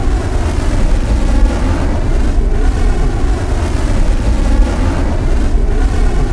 PlayerRocketThrust.wav